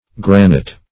Granite \Gran"ite\ (gr[a^]n"[i^]t), n. [It. granito granite,